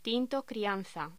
Locución: Tinto crianza
voz